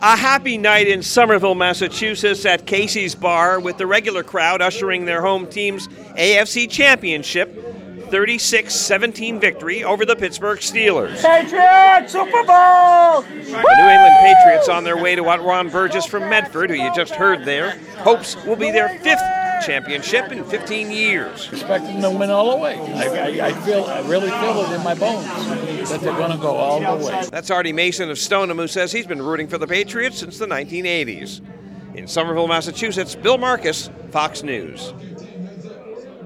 New England Patriots fans cheer AFC Championship victory over Steelers